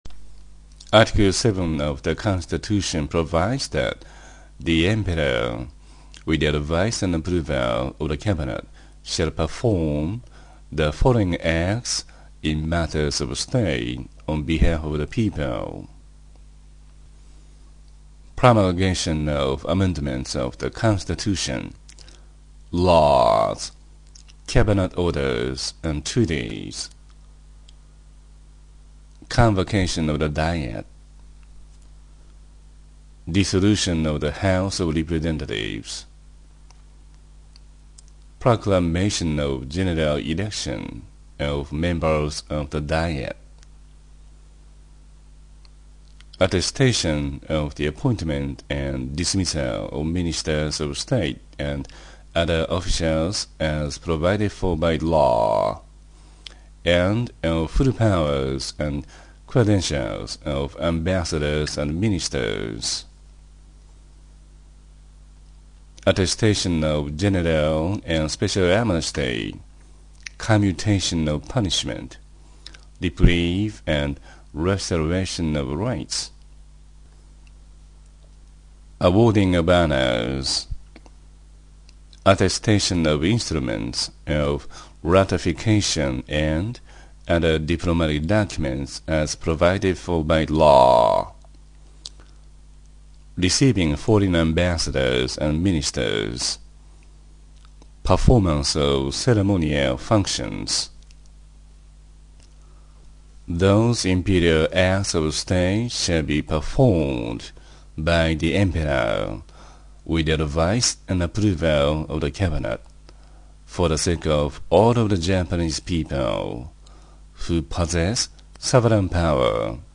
英語音声講義